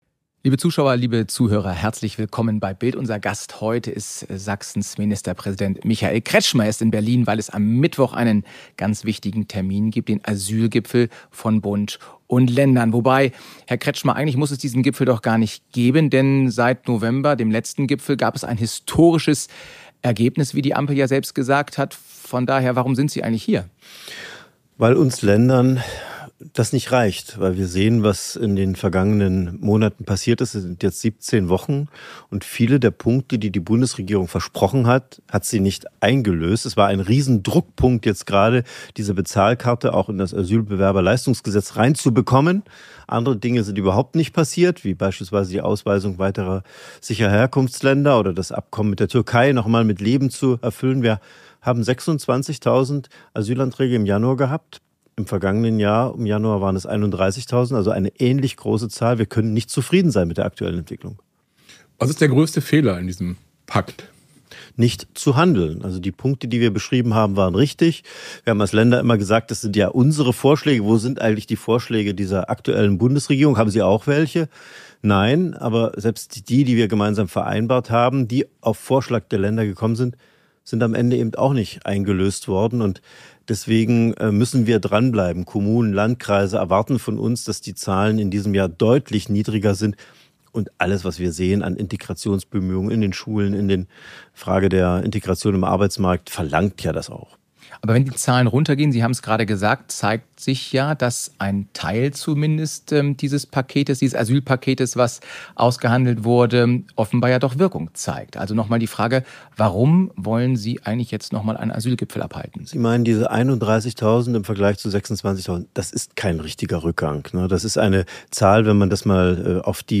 Ministerpräsident Michael Kretschmer im großen BILD-Interview.